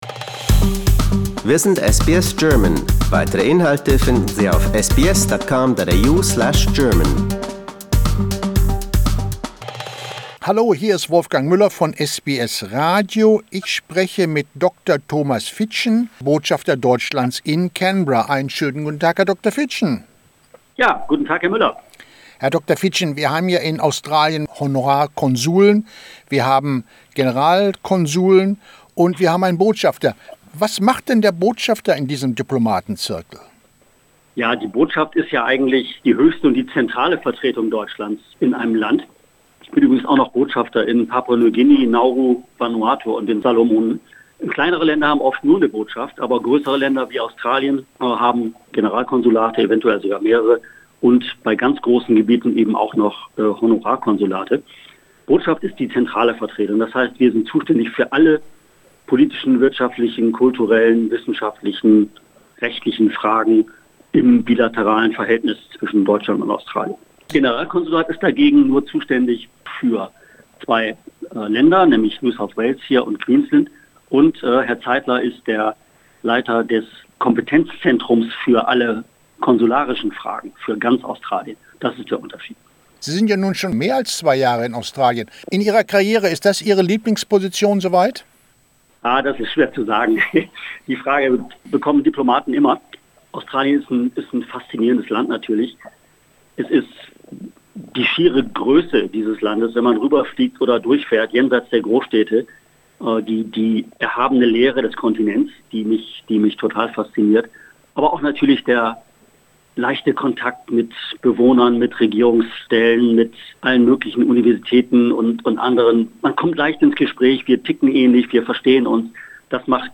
Interview with the German Ambassador